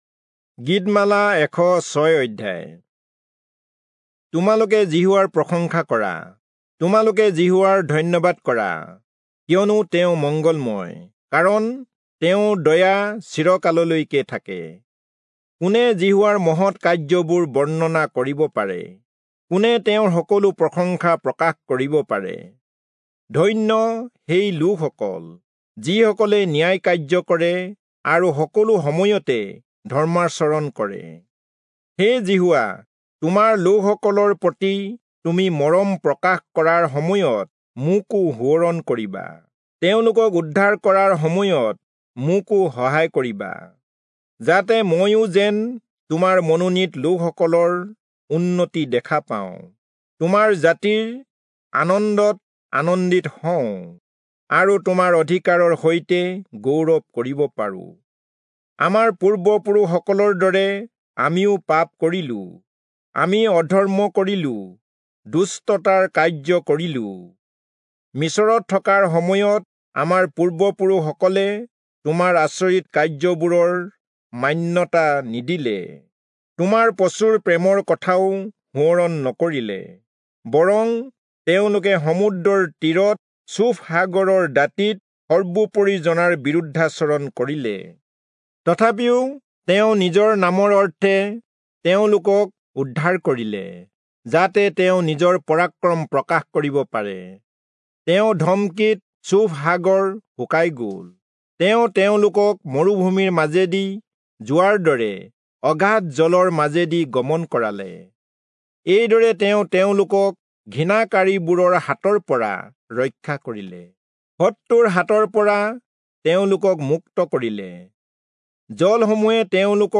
Assamese Audio Bible - Psalms 115 in Asv bible version